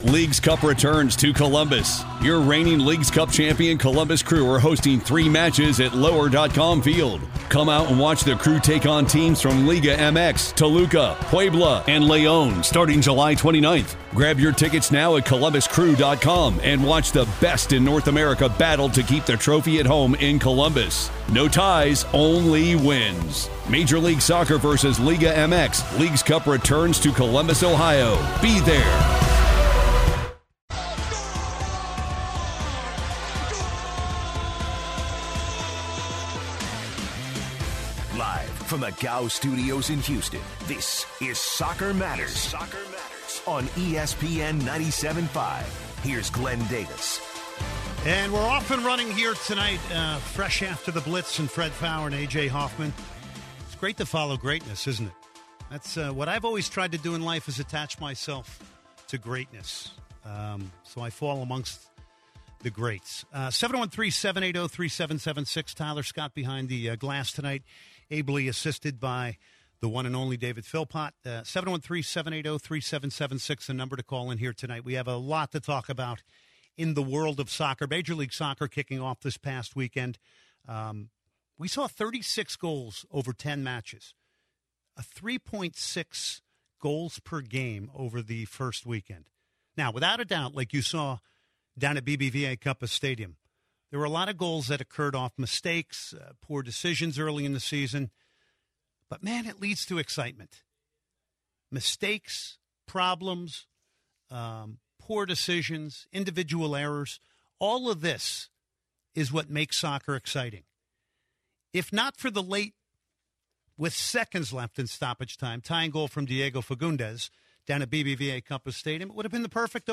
Houston caller talks about the player of the game Giles Barnes, captain of the Dynamos, scoring their last goal, and how he was disappointed on his performance but he believes Will Bruin should be more recognized.